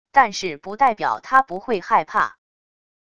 但是不代表她不会害怕wav音频生成系统WAV Audio Player